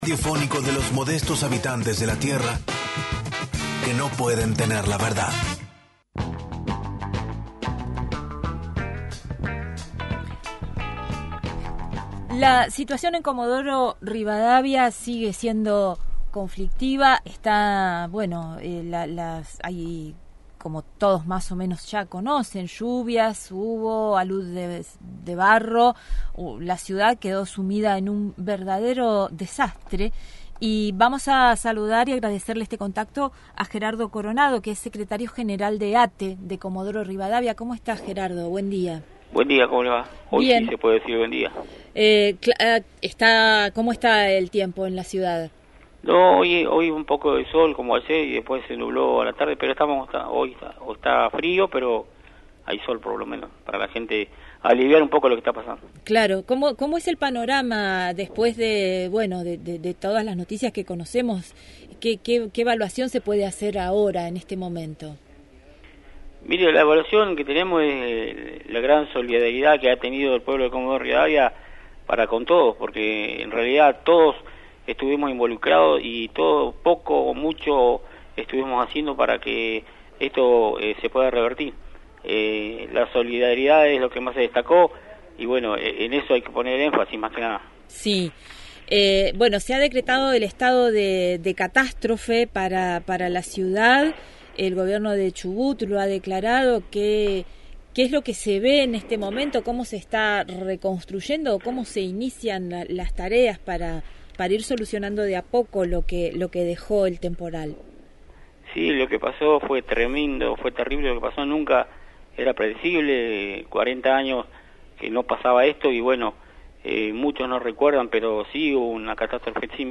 Programa: No Se Sabe. Conducción: